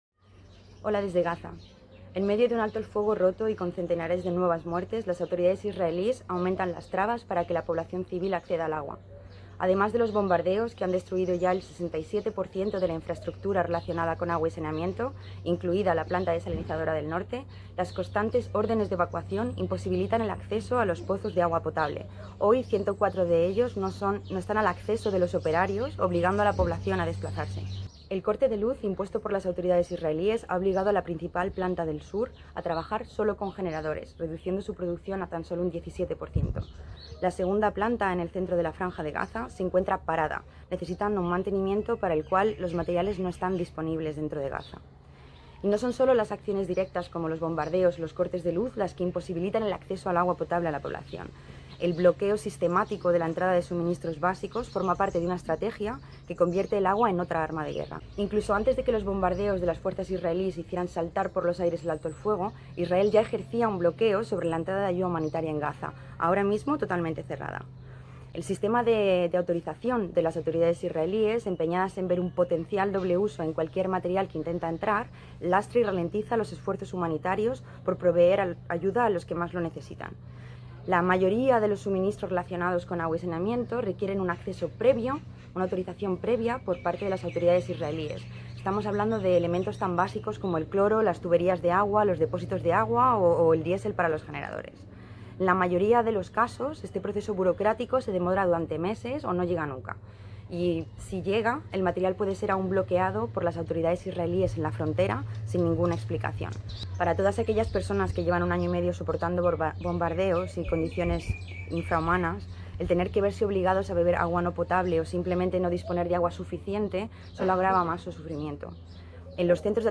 Audio con declaraciones en español